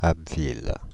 Abbeville (French: [abvil]